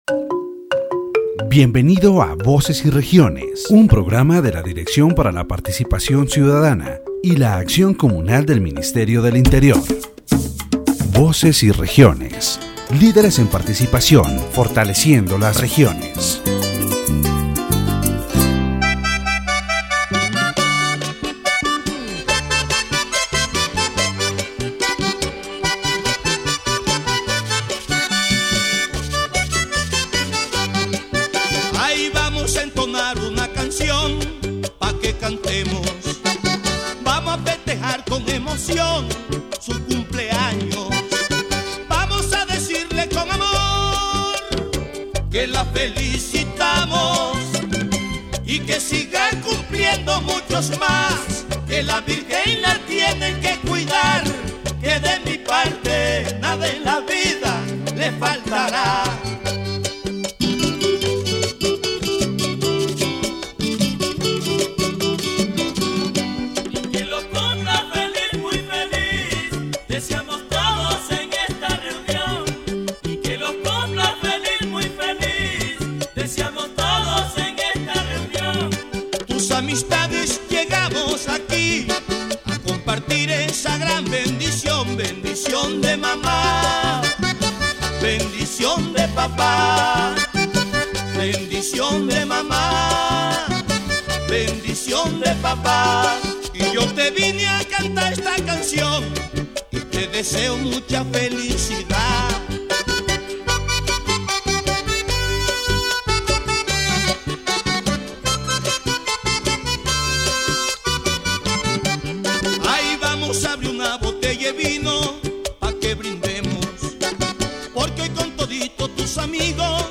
The interview delved into the development of public youth policies, access to higher education in La Guajira, and the cultural challenges young people face when migrating for professional training.